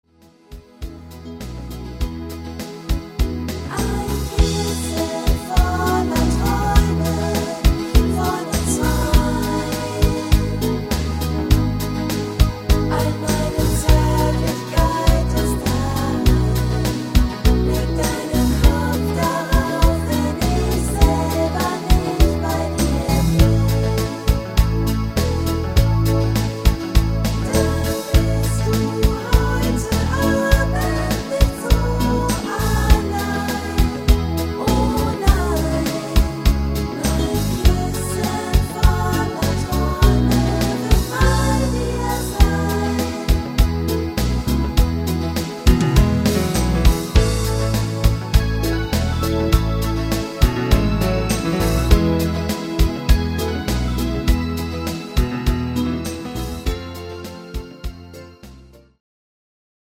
Rhythmus  Medium Beat
Art  Schlager 90er, Deutsch